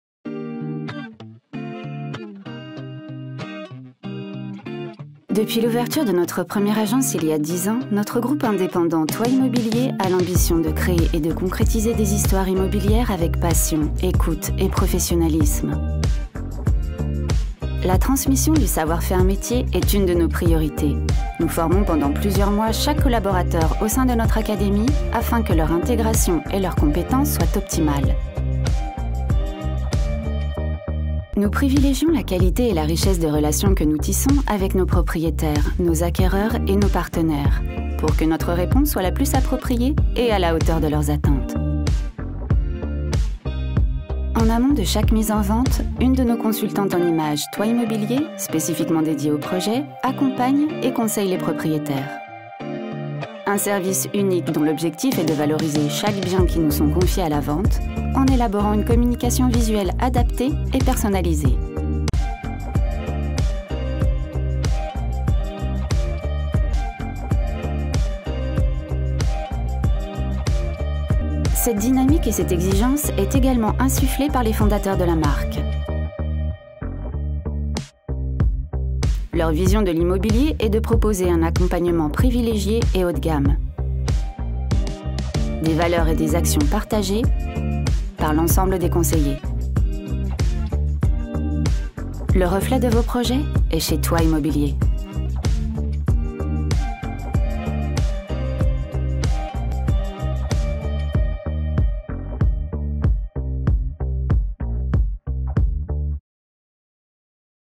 INSTITUTIONNEL (Toit immobilier) – adulte - medium - présentatrice - souriant/amicale